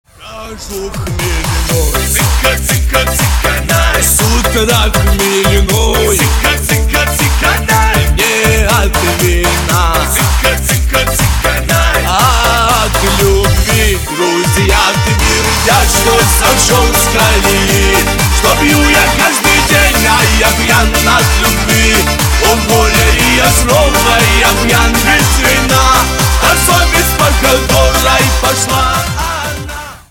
• Качество: 192, Stereo
громкие
веселые
быстрые
кавказские
шансон